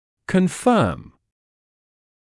[kən’fɜːm][кэн’фёːм]подтверждать, подкреплять